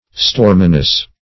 Storminess \Storm"i*ness\, n.